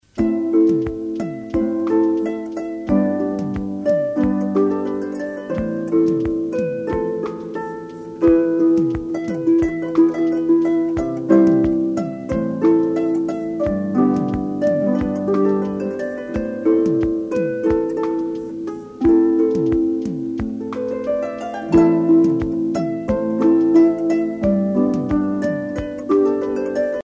danse : basse danse (renaissance)
bal, dancerie
Pièce musicale éditée